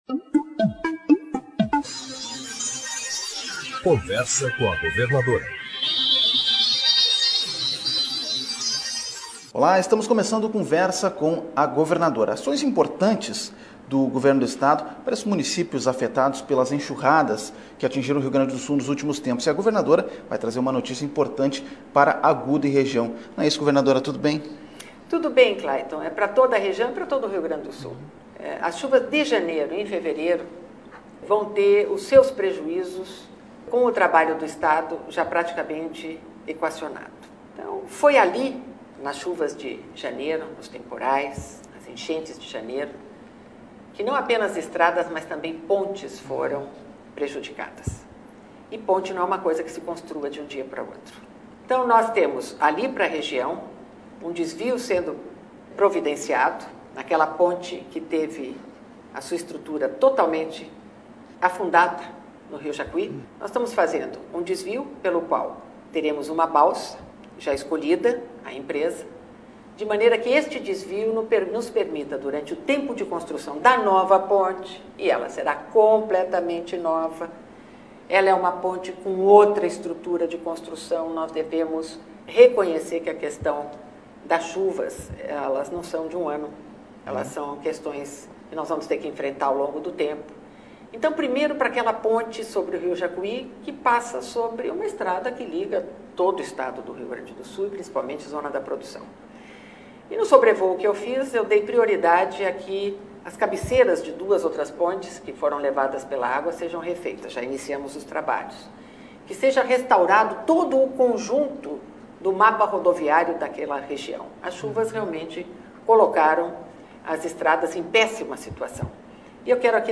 No Conversa com a Governadora, Yeda Crusius destaca as ações do governo do Estado no atendimento das comunidades afetadas por eventos climáticos que atingiram o Estado nos últimos meses.